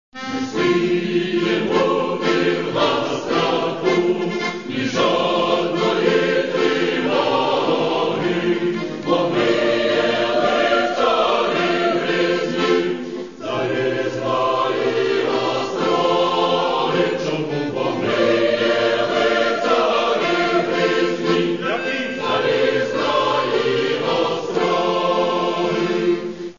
Українські народні пісні